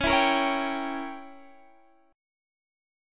Dbdim7 chord